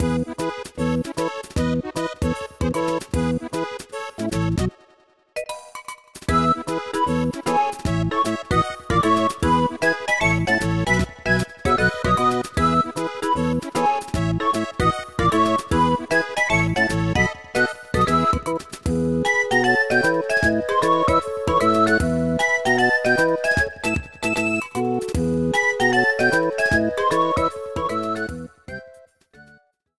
Background music
Reduced length to 30 seconds, with fadeout.